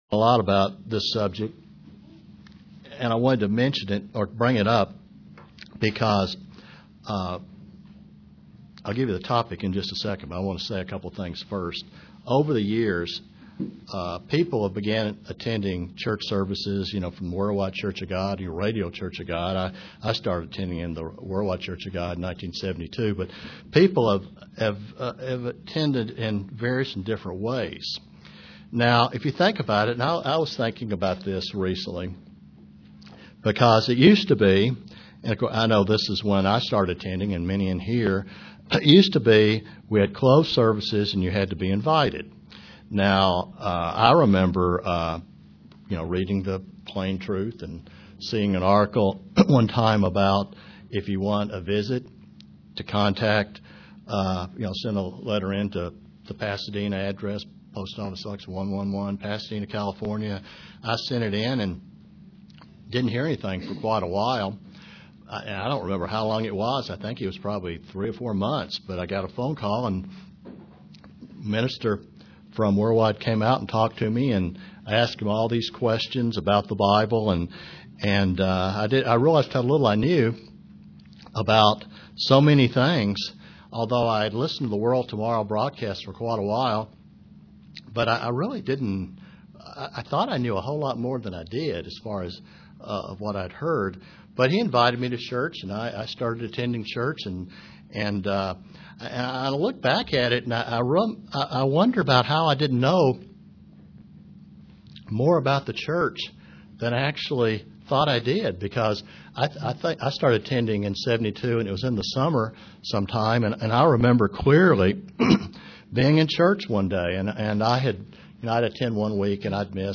Print Many in the world want religion to be about doing whatever makes them feel good about themselves UCG Sermon Studying the bible?